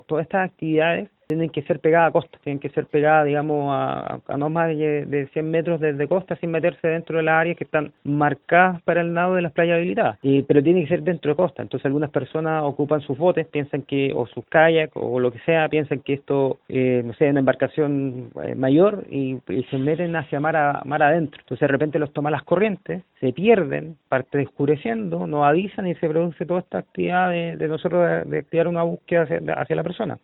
Ante esta situación, el Capitán de Fragata Cristian Díaz, Gobernador Marítimo (s) de Talcahuano, realizó un llamado a evitar conductas temerarias y a respetar las medidas de seguridad en el mar.